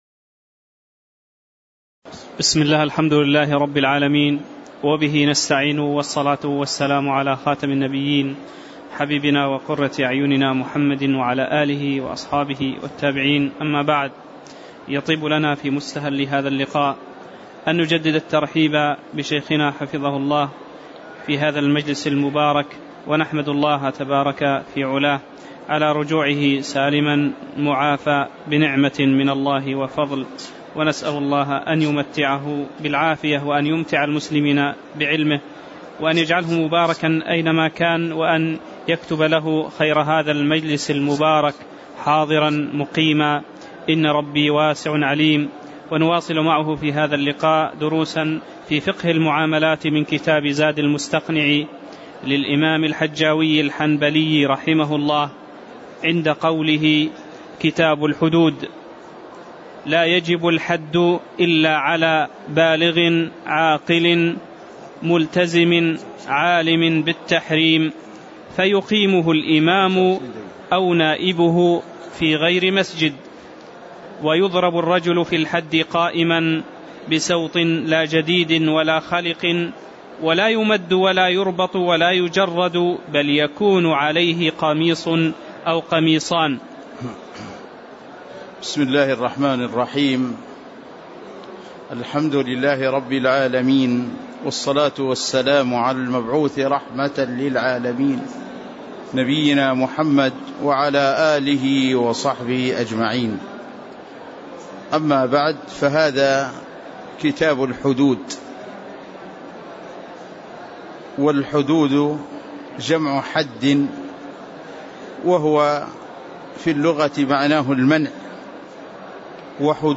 تاريخ النشر ٩ جمادى الأولى ١٤٣٨ هـ المكان: المسجد النبوي الشيخ